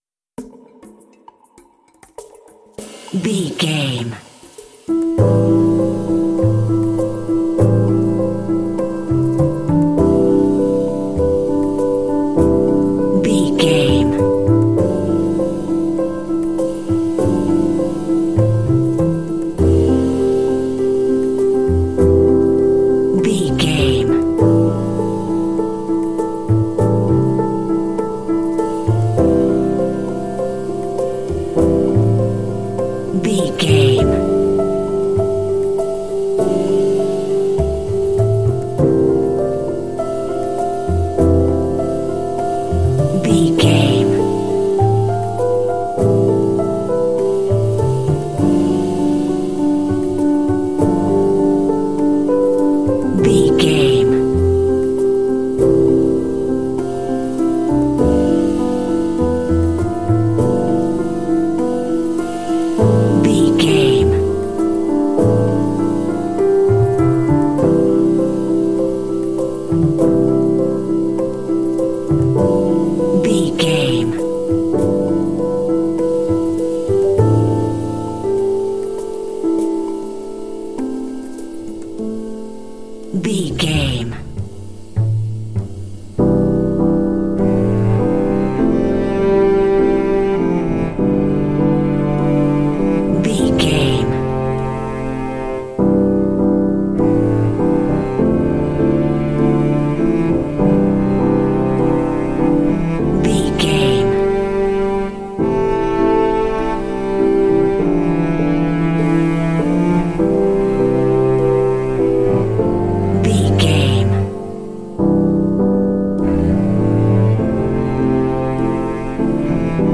Aeolian/Minor
mellow
melancholy
mournful
piano
cello
percussion
modern jazz